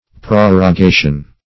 Prorogation \Pro`ro*ga"tion\, n. [L. prorogatio: cf. F.